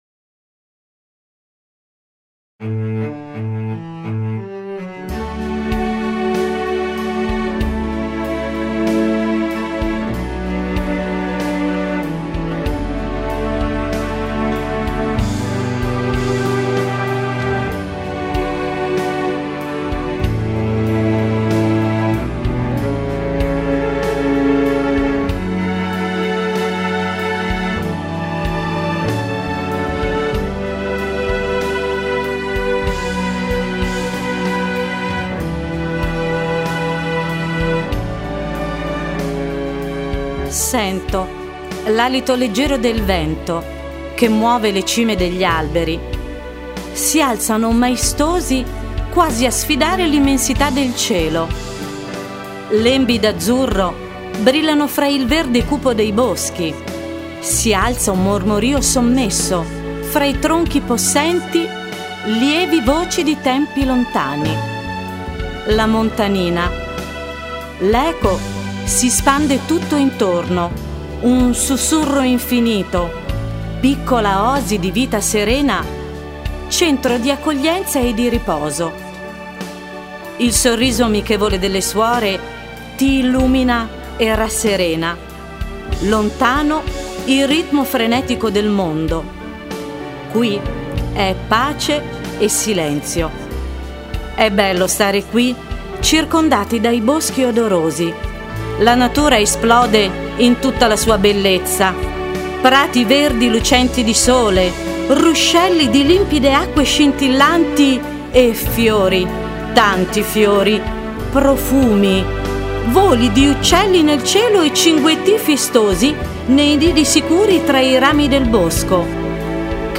Trasposizione in musica e voce della poesia di Lucia Meneghini